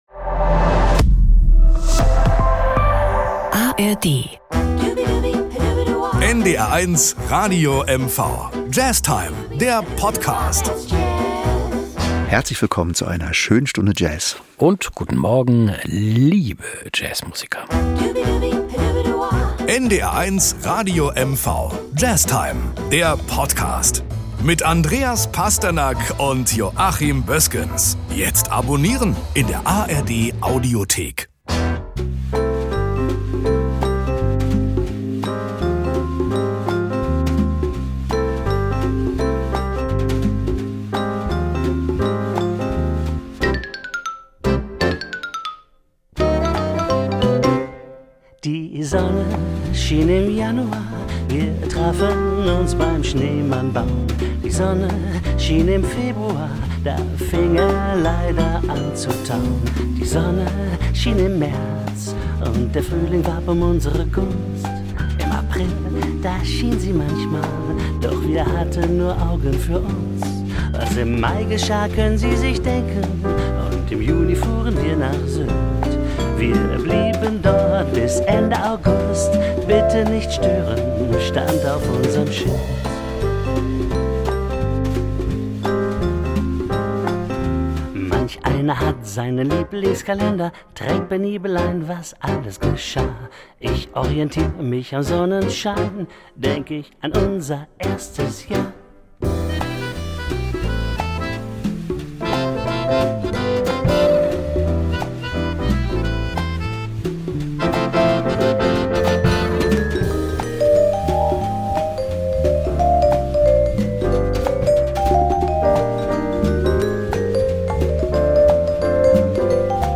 mit dem exotischen Sound